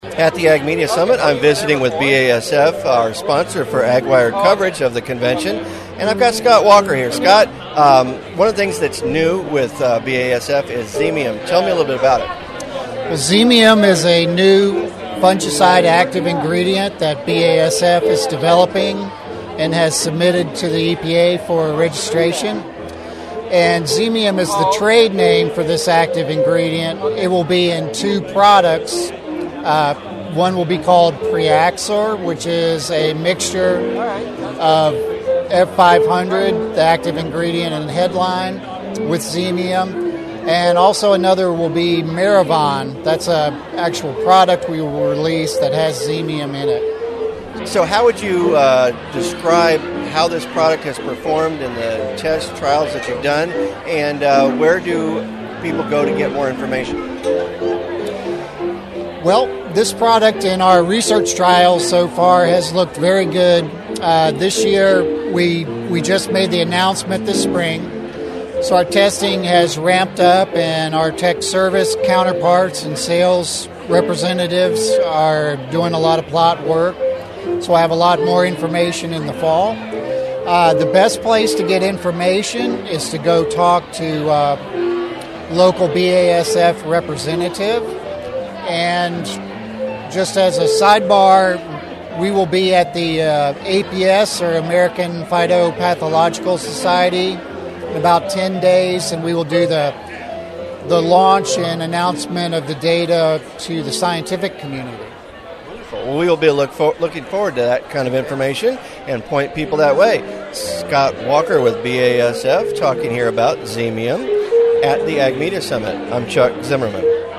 Meet three of my amigos from the Ag Media Summit.